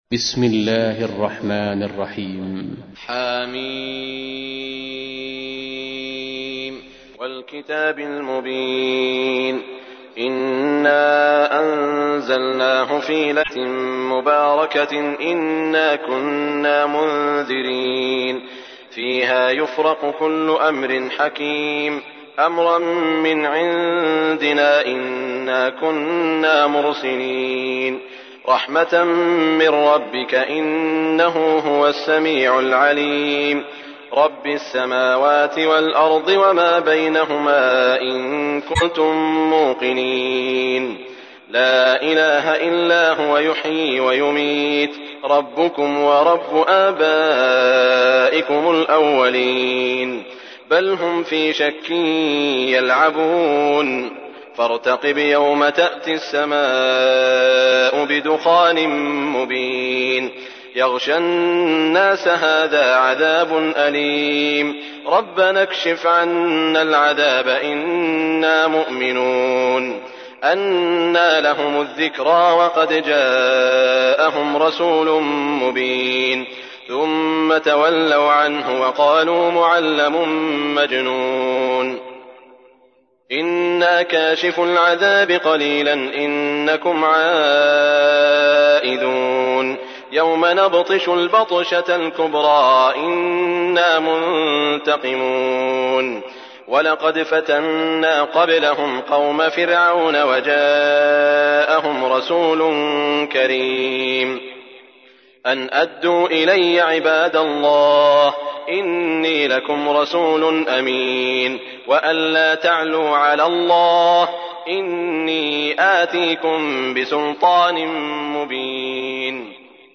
تحميل : 44. سورة الدخان / القارئ سعود الشريم / القرآن الكريم / موقع يا حسين